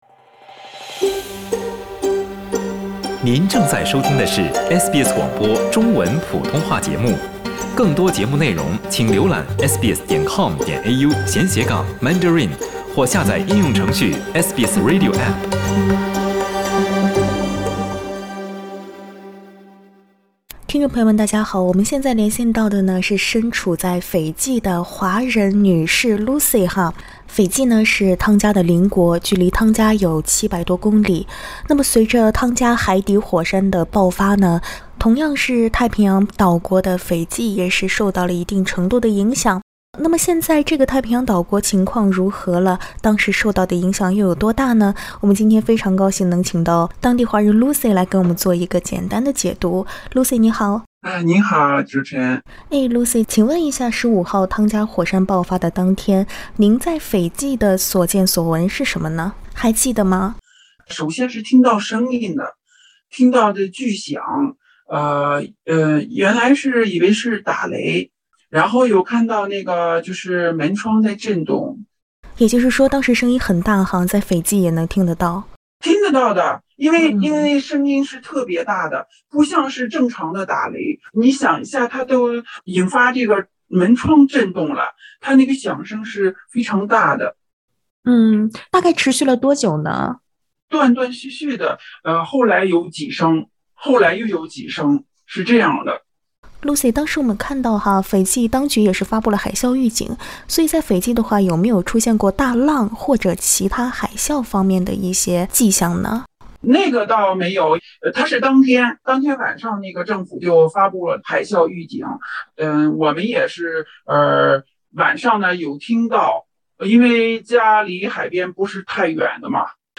请听采访： LISTEN TO 华人亲述：汤加火山爆发，对邻国斐济影响几何？